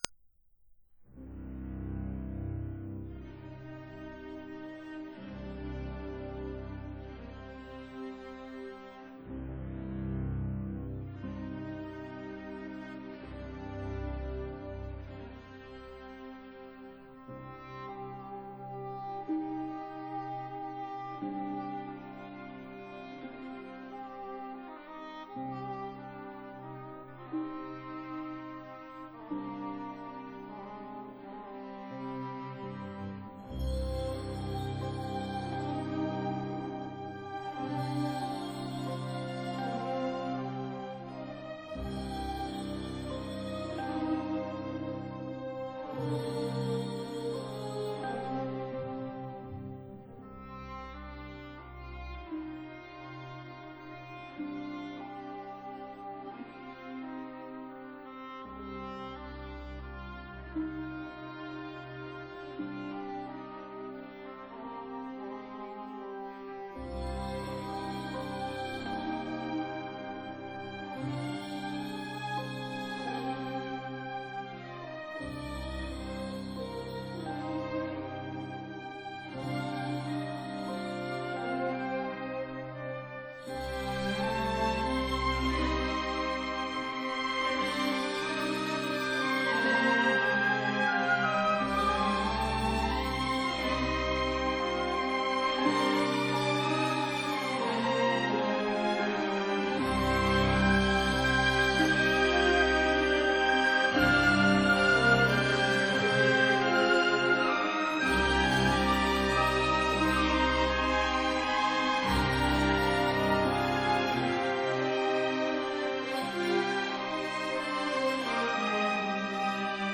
for piano and orchestra